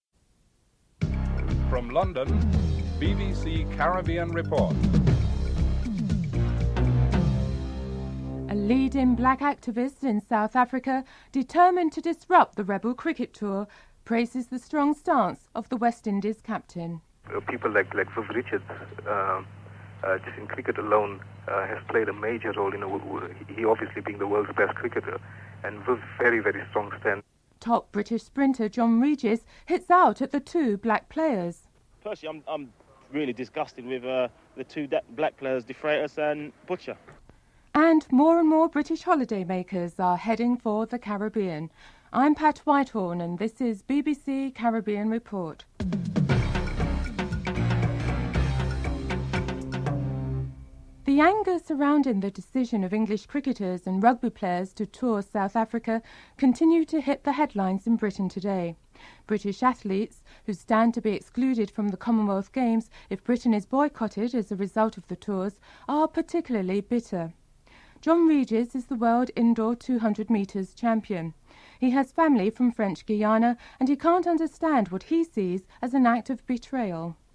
1. Headlines (00:00-00:50)
3. Financial news (05:27-07:07)